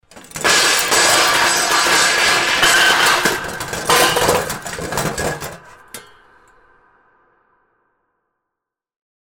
Звуки падения, грохота
звук как будто упало на пол что-то большое железное и его много